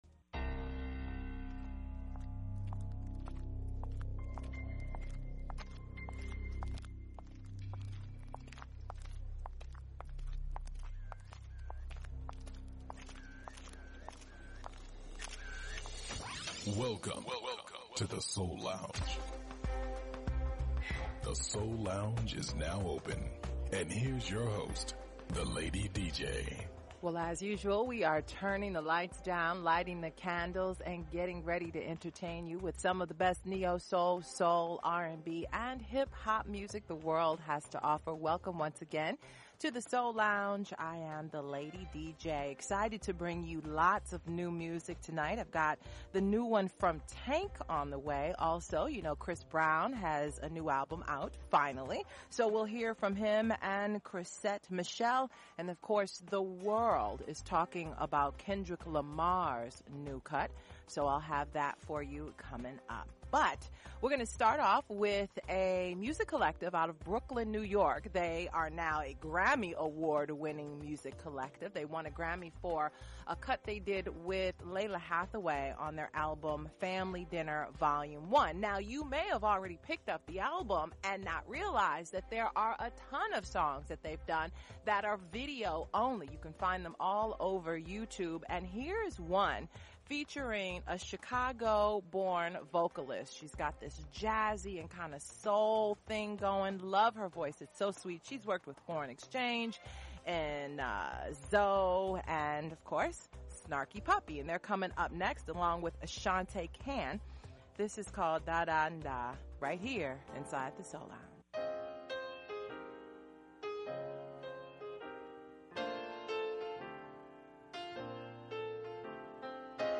Classic Soul